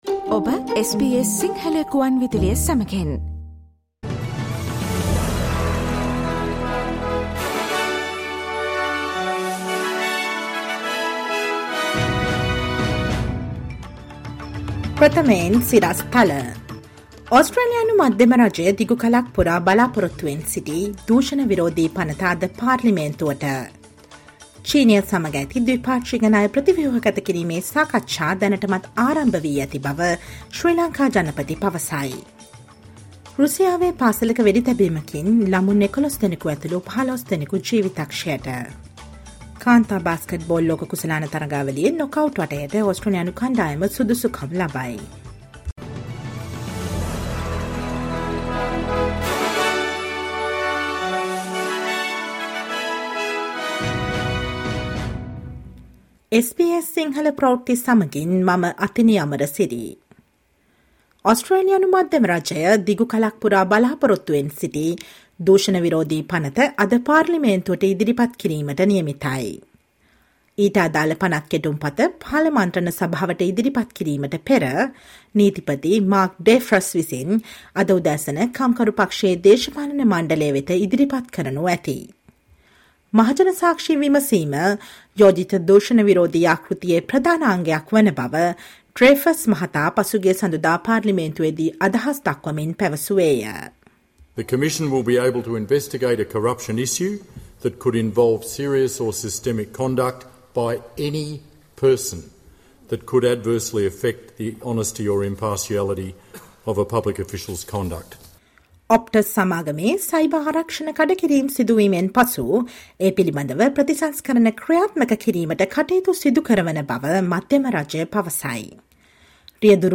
Listen to the latest news from Australia, Sri Lanka, and across the globe, and the latest news from the sports world on SBS Sinhala radio news – Tuesday, 27 September 2022.